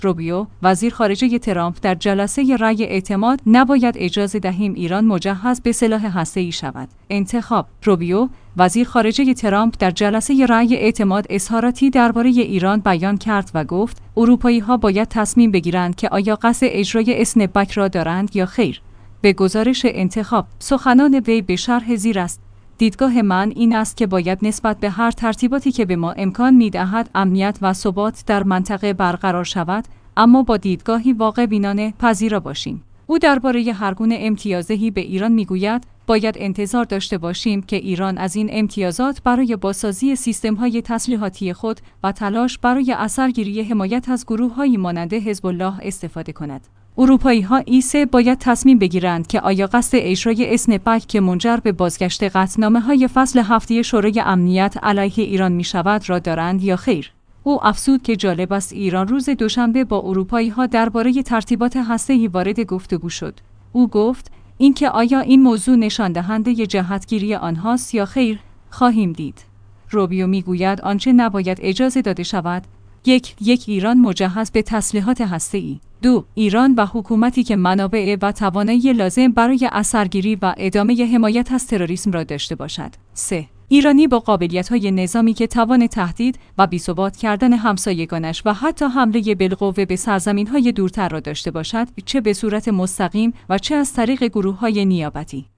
روبیو، وزیرخارجه ترامپ در جلسه رای اعتماد: نباید اجازه دهیم ایران مجهز به سلاح هسته‌ای شود